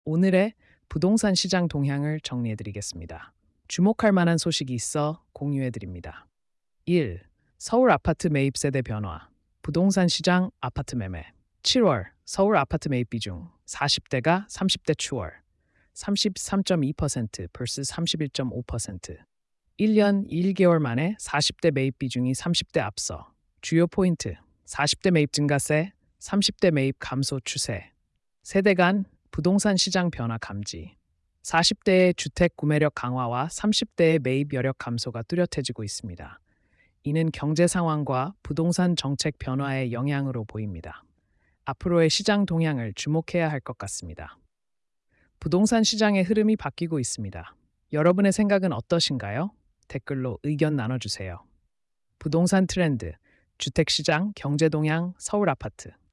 openai-tts-output.mp3